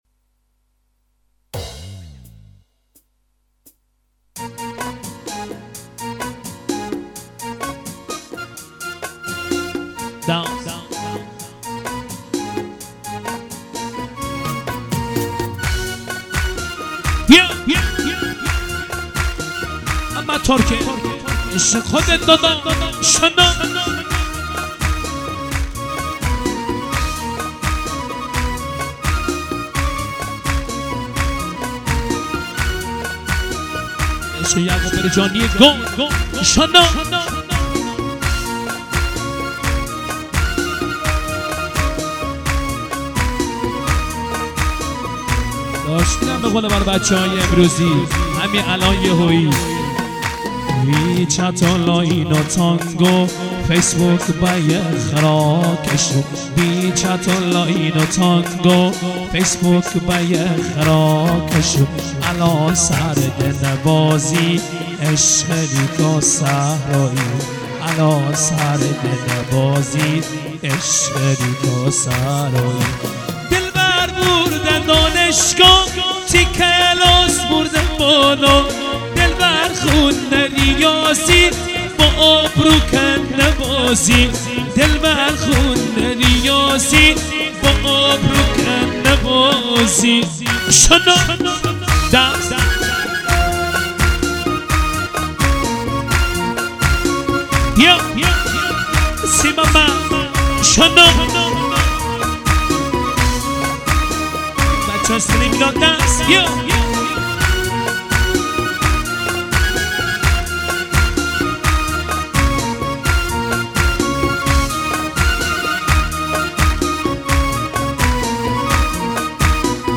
آهنگ شاد , خوانندگان مازنی